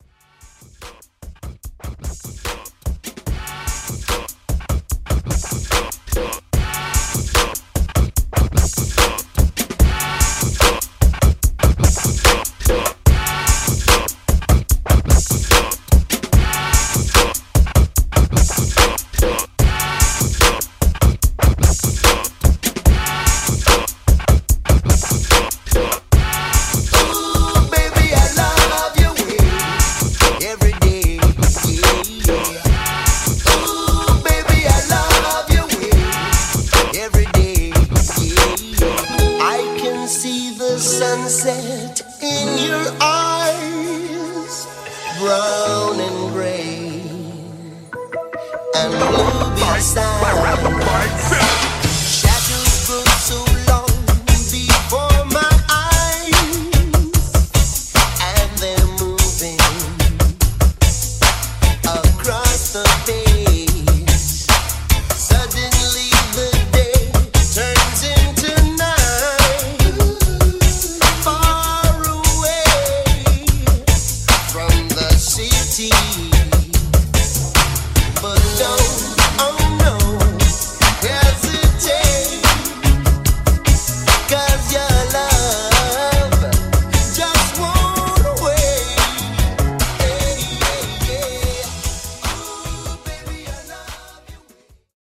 90’s Reggae Re-Drum)Date Added